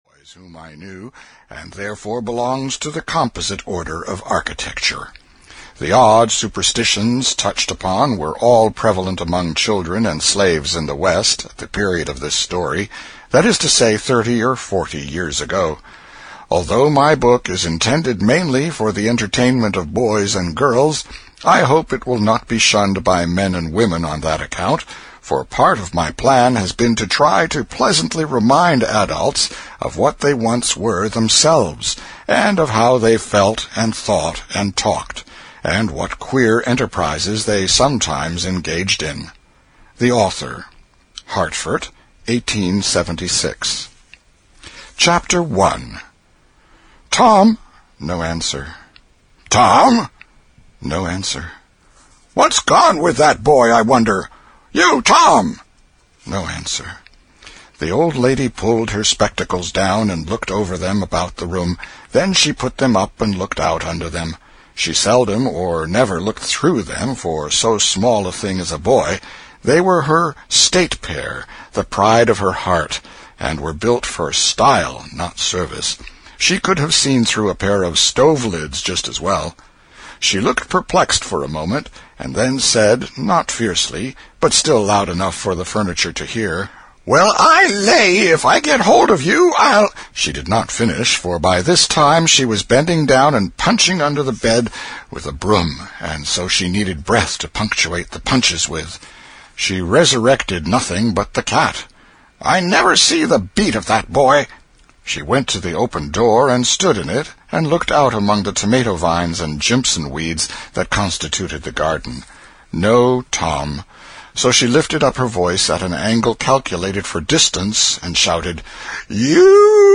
The Adventures of Tom Sawyer (EN) audiokniha
Ukázka z knihy